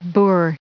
added pronounciation and merriam webster audio
832_boor.ogg